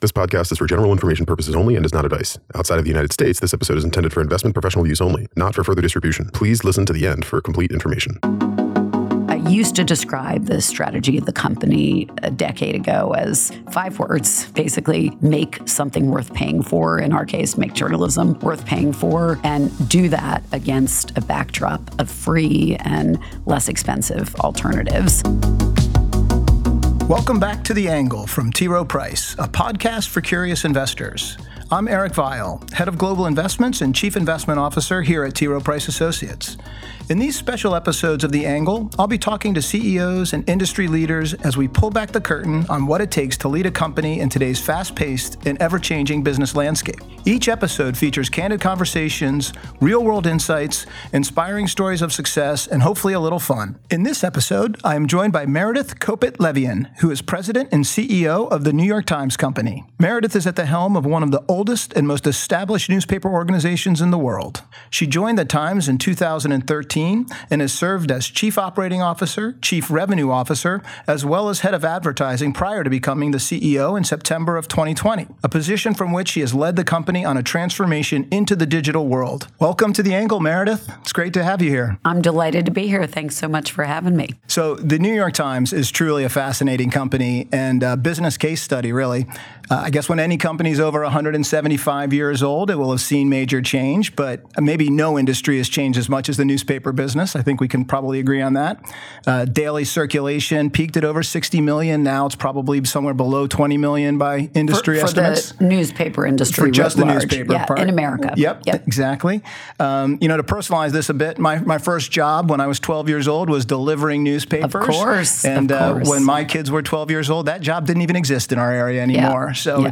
The Angle from T. Rowe Price - The Long View: Interview with